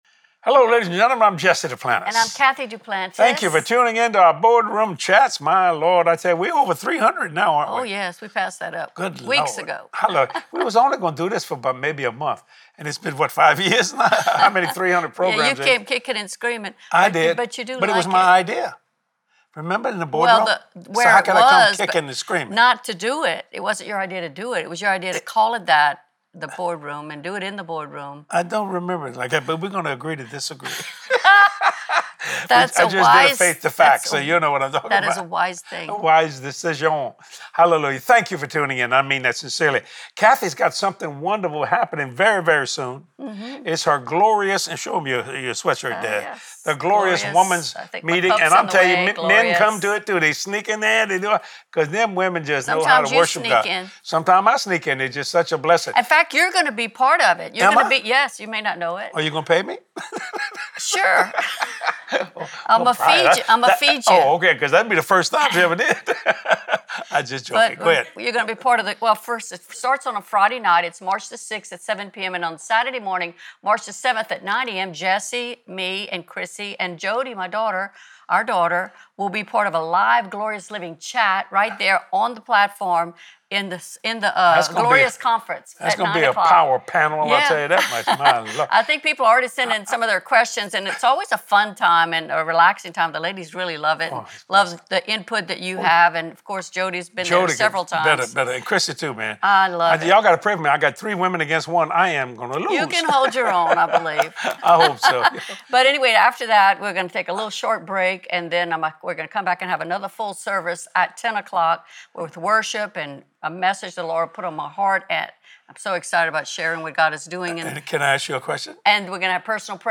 What does ABOUNDING mean? Discover the truth about standing firm and secure in your faith as you watch this anointed teaching